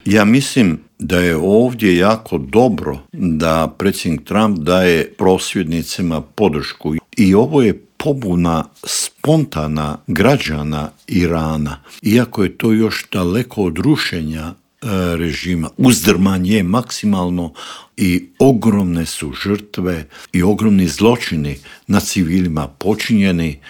ZAGREB - Uoči 34. godišnjice međunarodnog priznanja Hrvatske i 28. obljetnice završetka mirne reintegracije hrvatskog Podunavlja u Intervju Media servisa ugostili smo bivšeg ministra vanjskih poslova Matu Granića, koji nam je opisao kako su izgledali pregovori i što je sve prethodilo tom 15. siječnju 1992. godine.